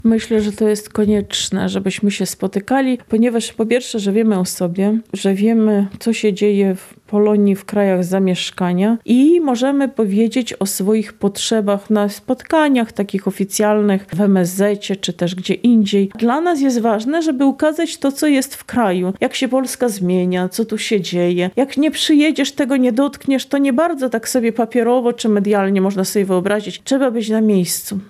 w rozmowie z nami